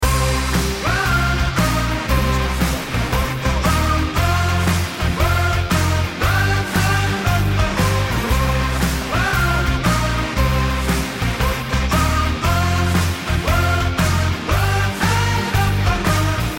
Energetic Indie Rock [Loop 2]